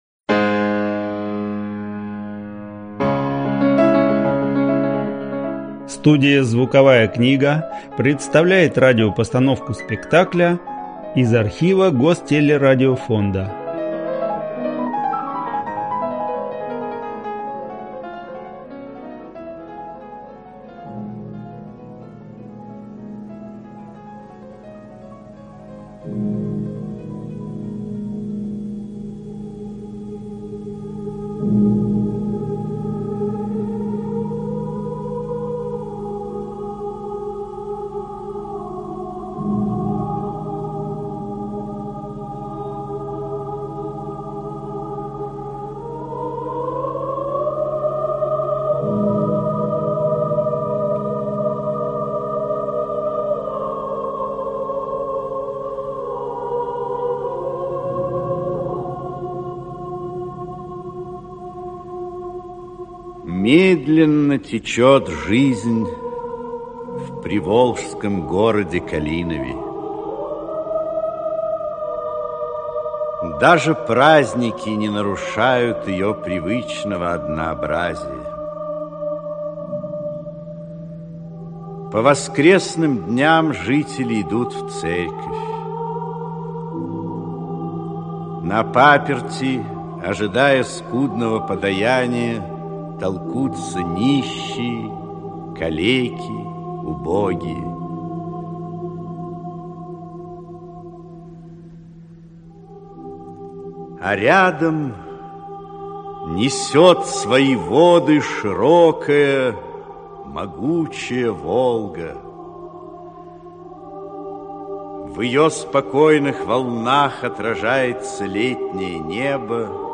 Аудиокнига Гроза (спектакль) | Библиотека аудиокниг
Aудиокнига Гроза (спектакль) Автор Александр Островский Читает аудиокнигу Актерский коллектив.